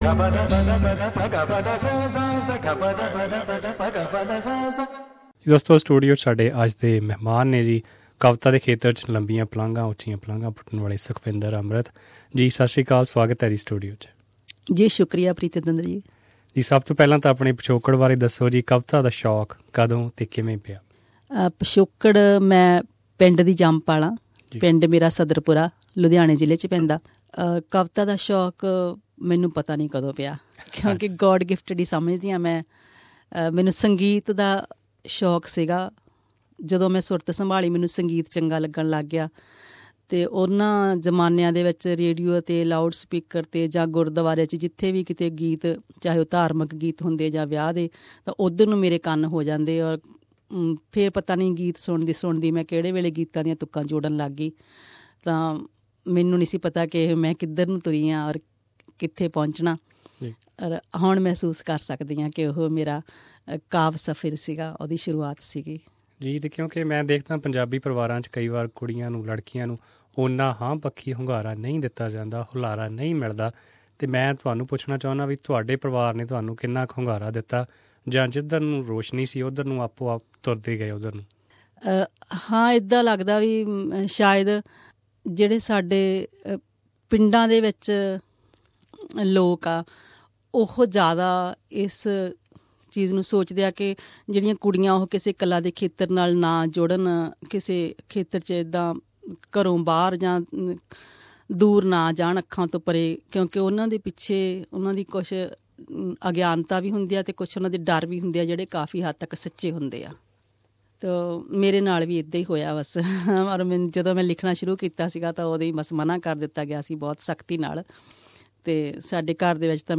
who was our studio guest at Melbourne.